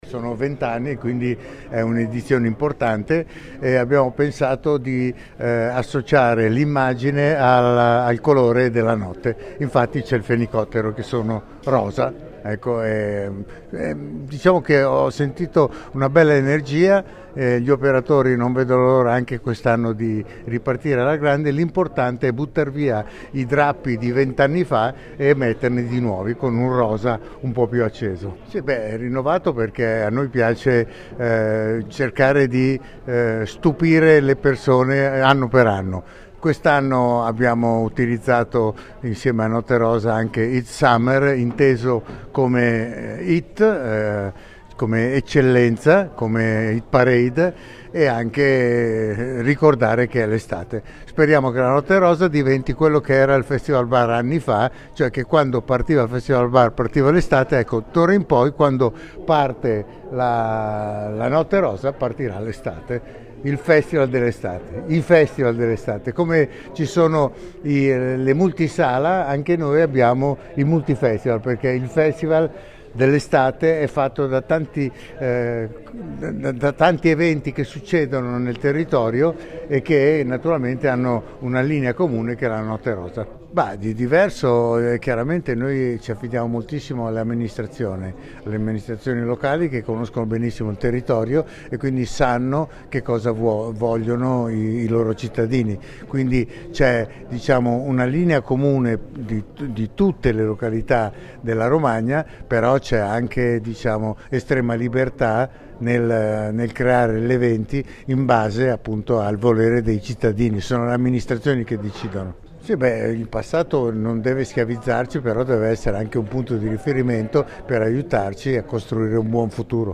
Ascolta l’intervista al brand ambassador di Visit Romagna Claudio Cecchetto: